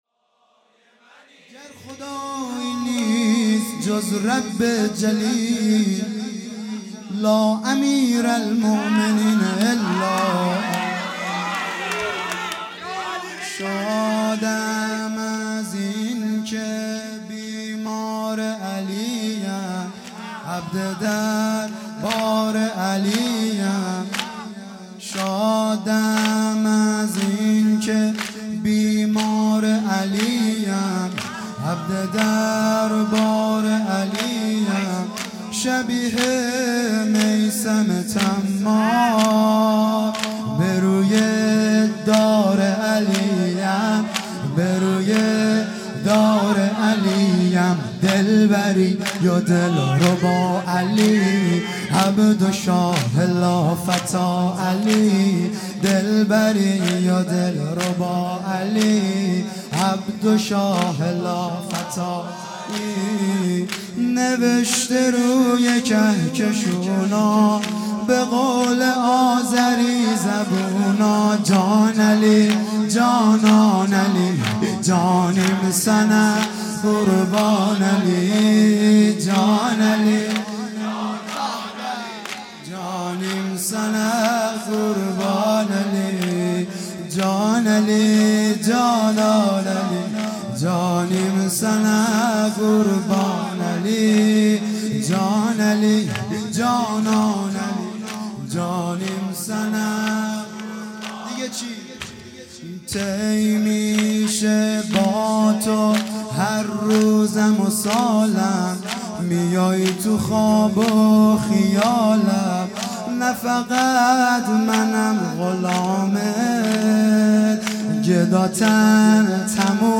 شب اول محرم